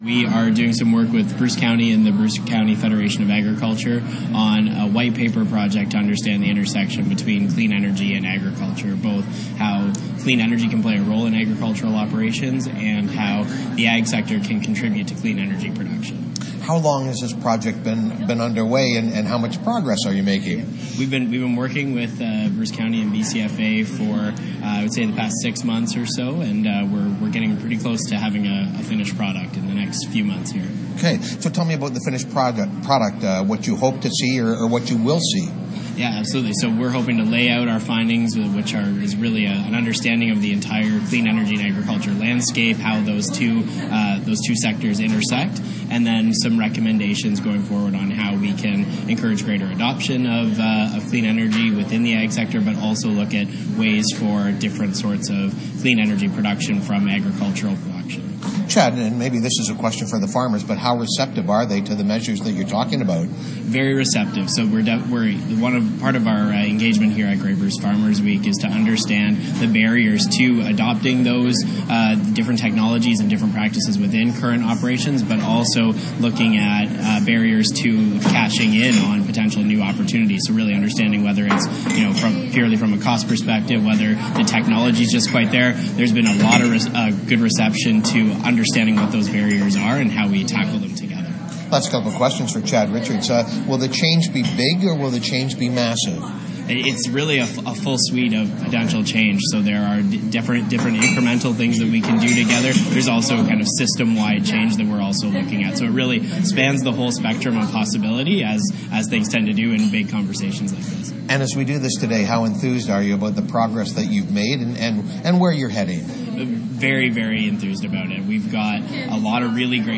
Clean Energy & Agriculture in Bruce County. Interview